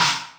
Index of /kb6/Akai_XR-20/Hats